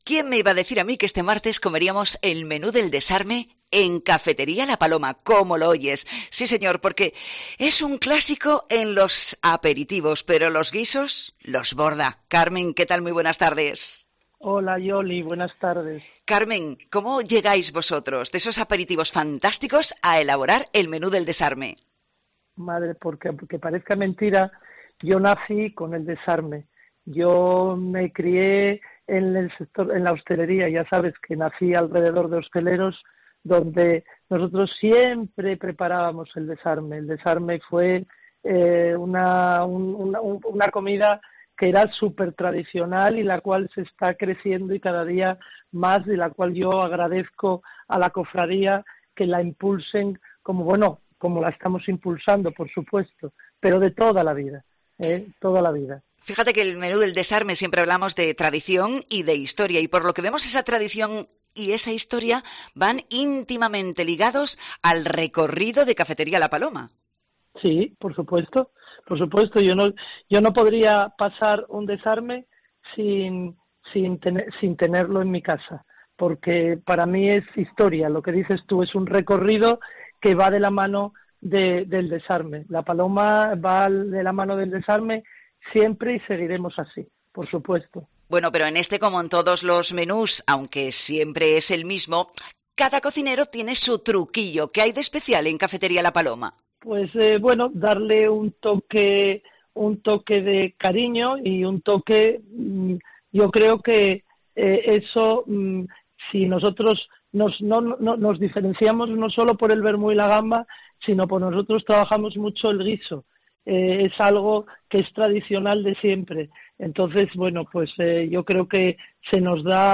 Jornadas del Desarme: entrevista